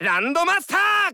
File:Fox voice sample SSB4 JP.oga
Fox_voice_sample_SSB4_JP.oga.mp3